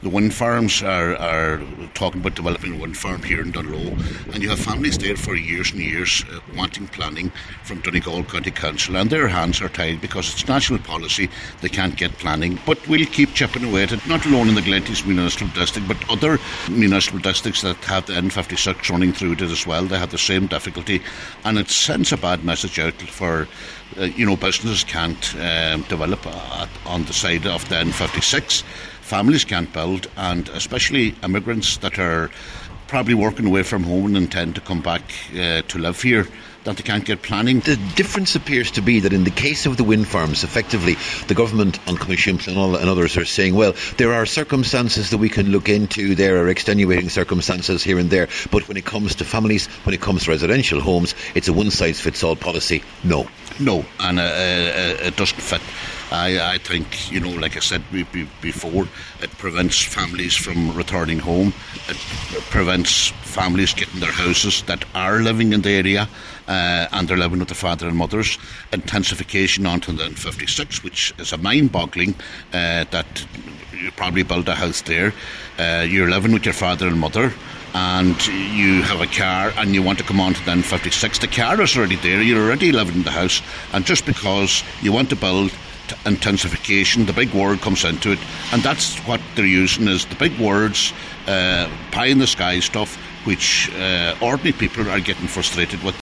Cllr John Sheamais O’Fearraigh told a meeting of Glenties Municipal District that a council which is due to meet with Transport Infrastructure Ireland in Dublin next month must put the issue of the N56 firmly on the agenda.